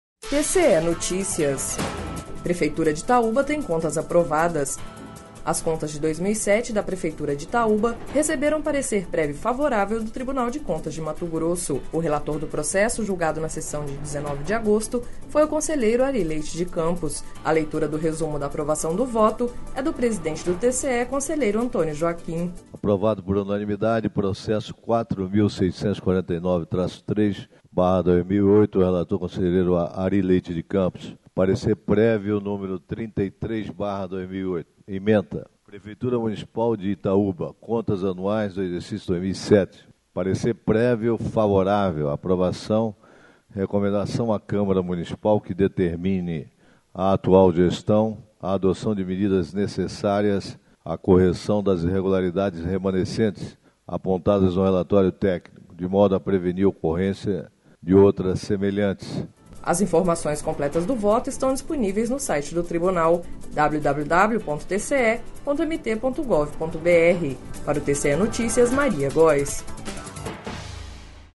Sonora: Antonio Joaquim - conselheiro presidente do TCE-MT